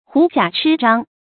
狐假鴟張 注音： ㄏㄨˊ ㄐㄧㄚˇ ㄔㄧ ㄓㄤ 讀音讀法： 意思解釋： 比喻倚仗別人，虛張聲勢。